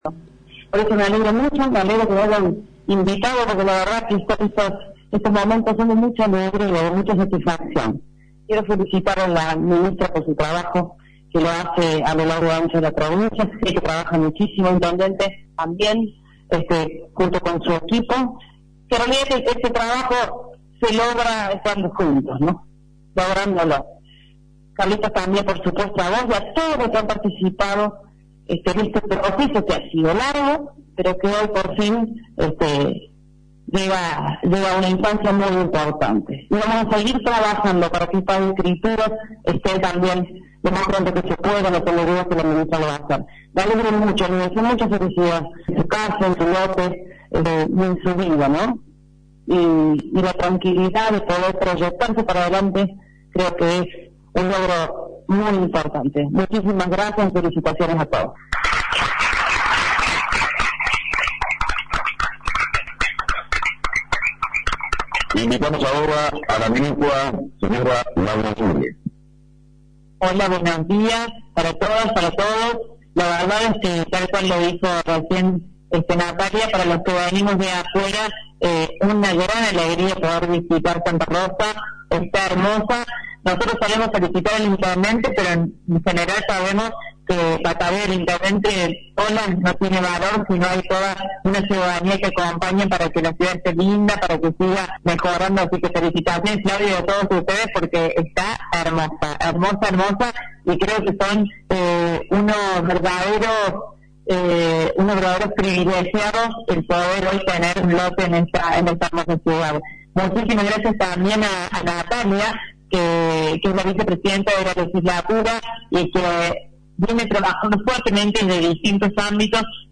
En un acto realizado en el Hotel Eva perón que contó con la presencia de la Ministra de Promoción del Empleo y de la Economía Familiar , Laura Juré, 48 familias de la localidad recibieron sus boletos de compra vente. La funcionaria explicó que con 120 cuotas accesibles podrán acceder a su vivienda y el proyecto incorporará a nuevos beneficiarios hasta llegar al número de 76.